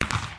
firework5.mp3